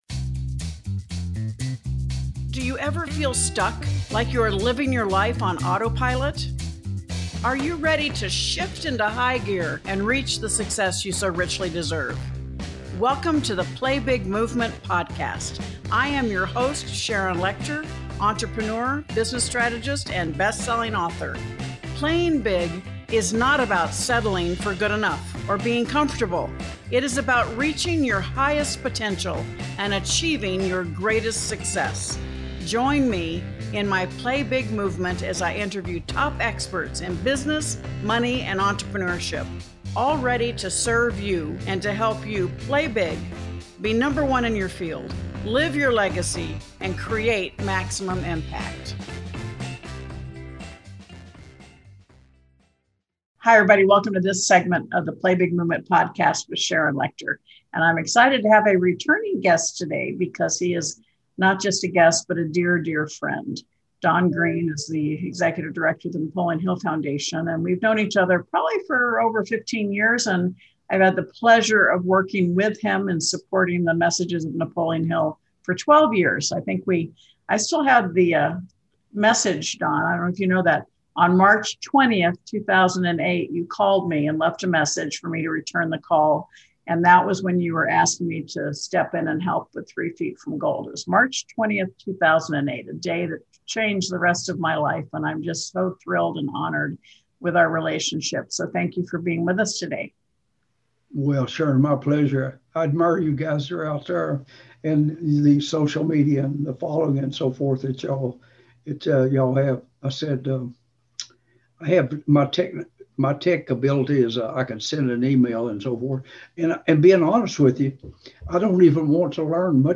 In this episode, we will have another conversation